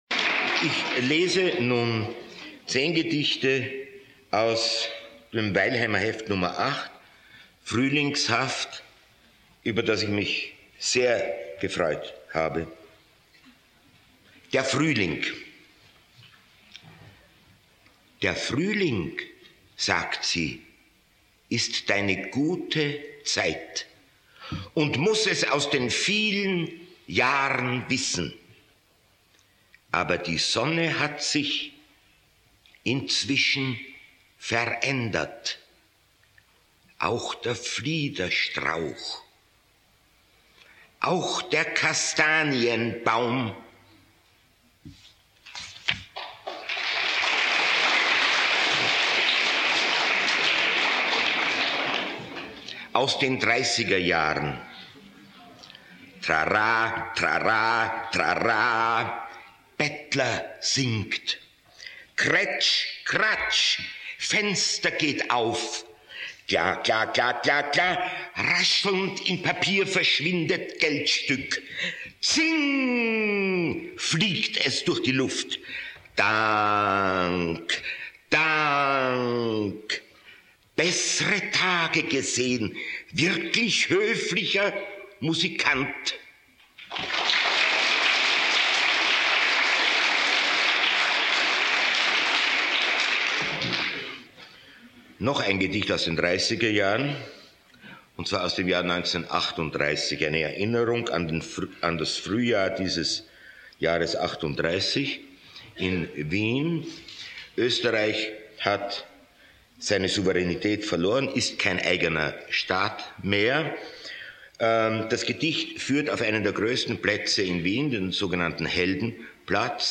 frühlingshaft - Ernst Jandl - Hörbuch